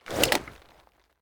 holster1.ogg